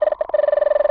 cartoon_electronic_computer_code_11.wav